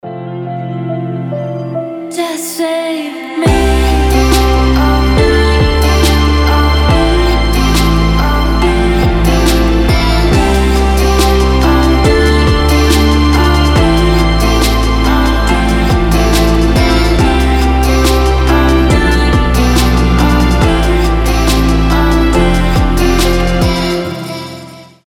• Качество: 320, Stereo
мелодичные
Electronic
спокойные
релакс
Chill Trap
Классный chill trap на будильник или спокойный звонок